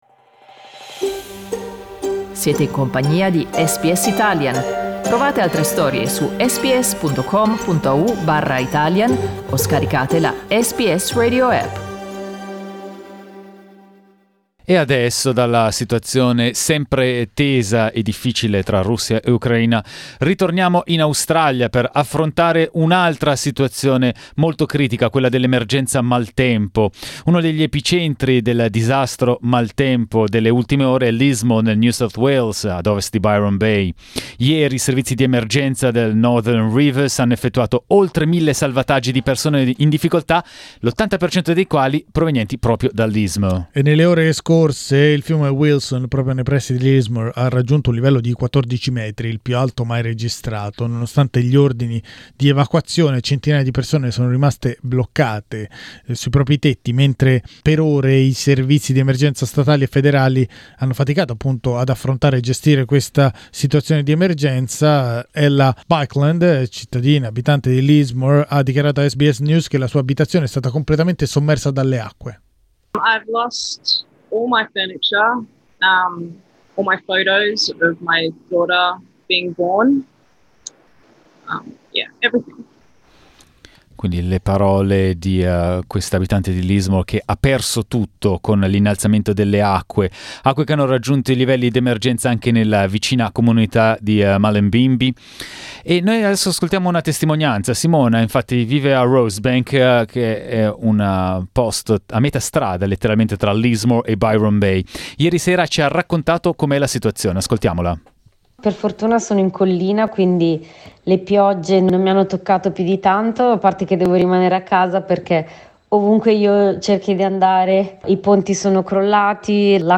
SBS Italian ha fatto il punto della situazione con testimonianze di italiani coinvolti, in collegamento da Rosebank, località a metà strada tra Lismore e Byron Bay, e Brisbane.